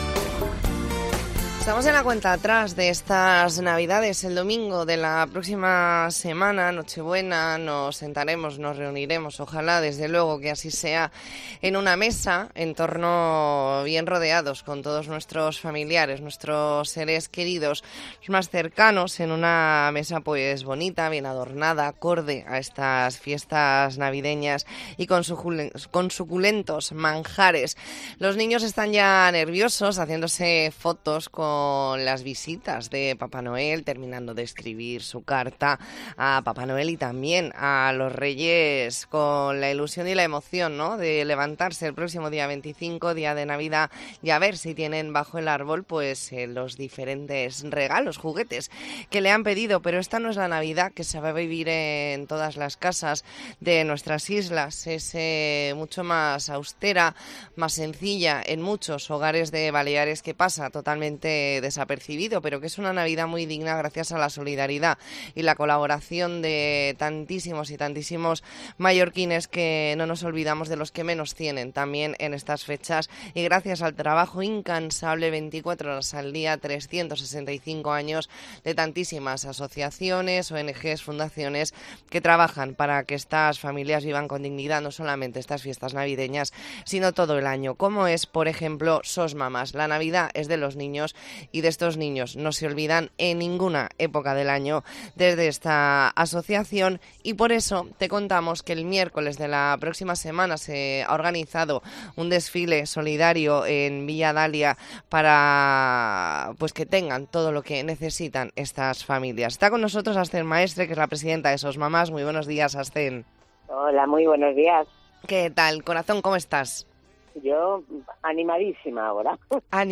Entrevista en La Mañana en COPE Más Mallorca, jueves 14 de diciembre de 2023.